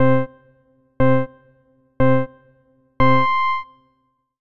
Pacer Beeps Normal.wav